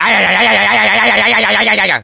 Wario gets electrocuted in Super Mario Strikers.
Wario_(Electrocution_2)_-_Super_Mario_Strikers.oga